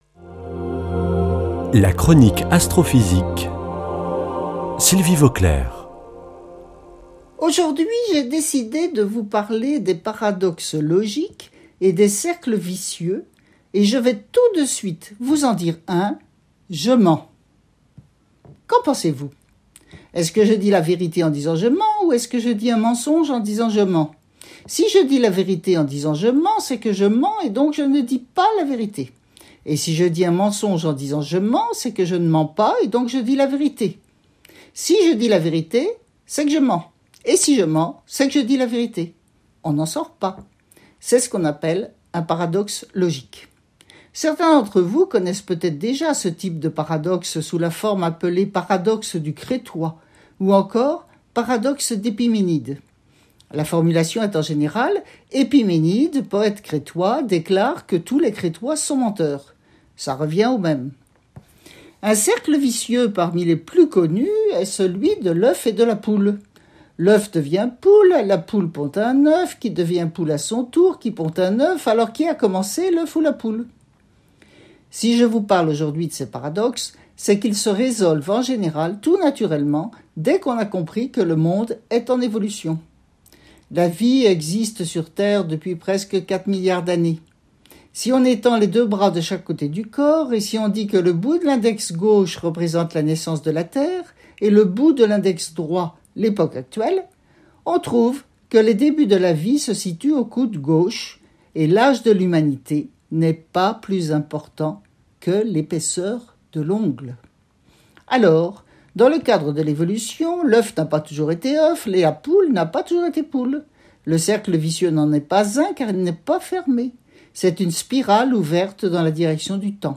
lundi 4 janvier 2021 Chronique Astrophysique Durée 3 min
Astrophysicienne